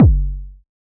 RDM_TapeB_SY1-Kick03.wav